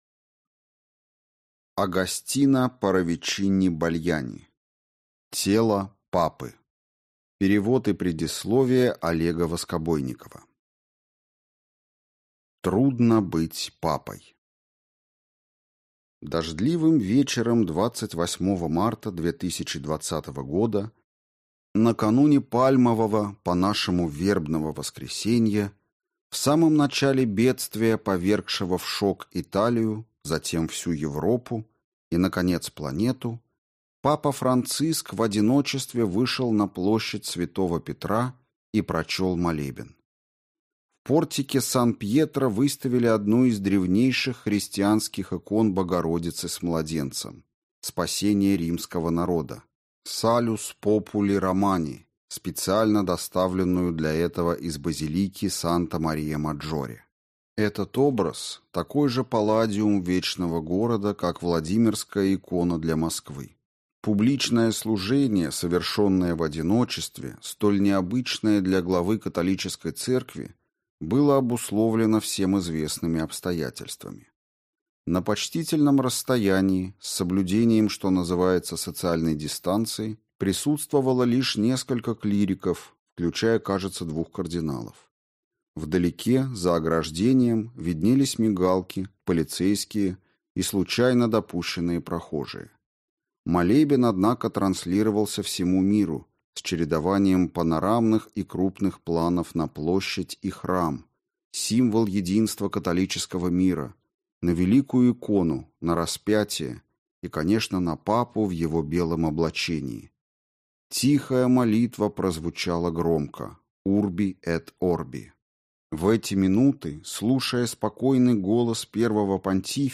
Аудиокнига Тело Папы | Библиотека аудиокниг
Читает аудиокнигу